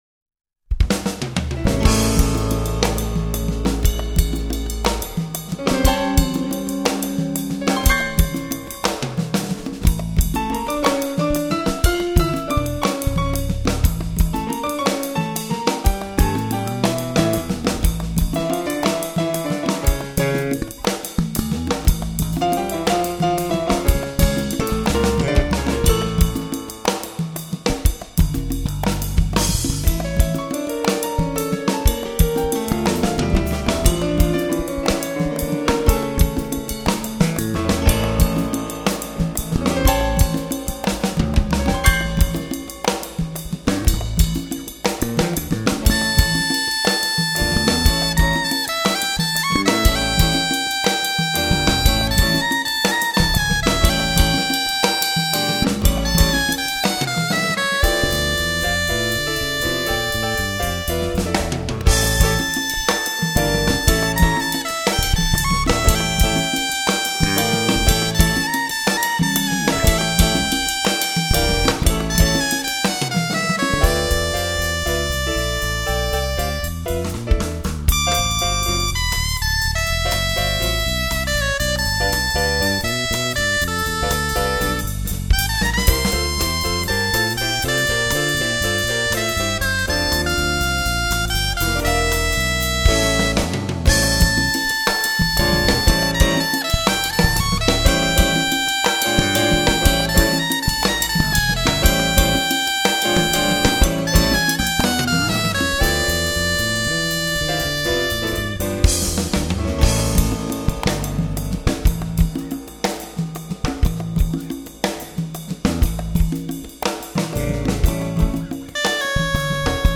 dolçaina
piano
bateria